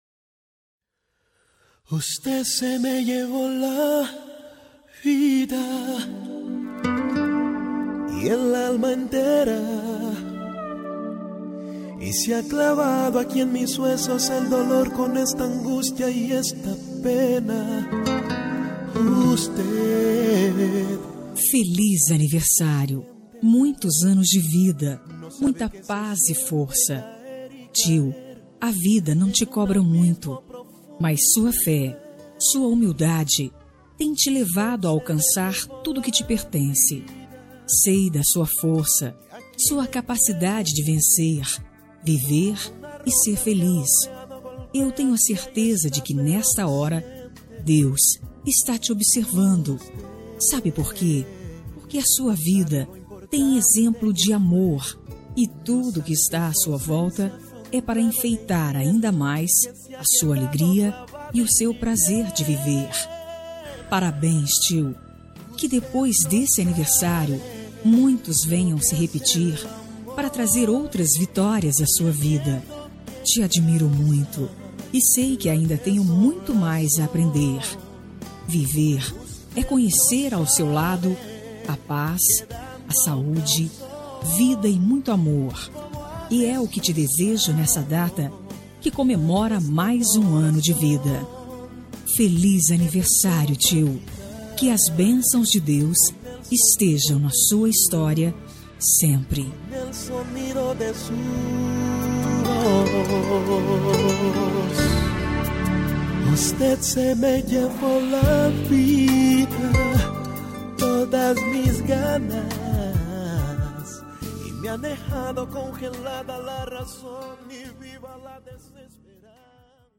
Aniversário de Tio – Voz Feminina – Cód: 925
925-tio-fem.m4a